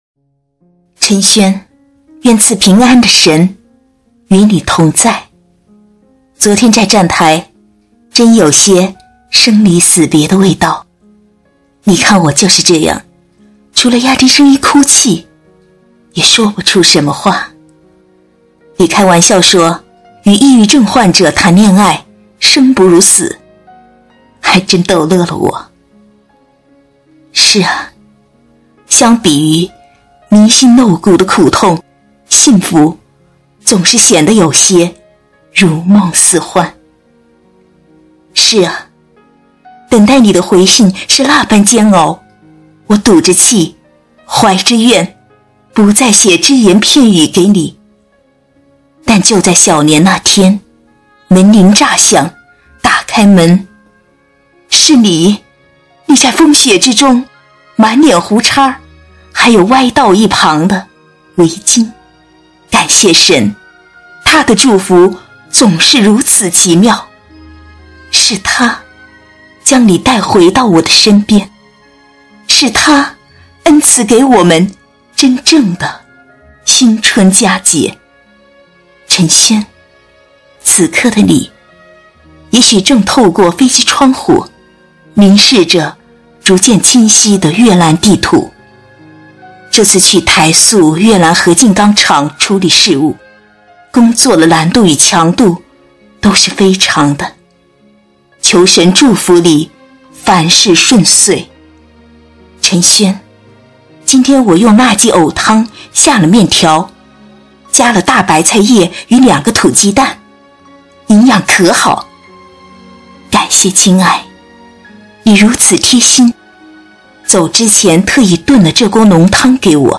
有声书《神的应许》耶稣成就应许(三)这便是神恩赐给我的甘甜